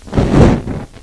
flamethrowerIgnite.ogg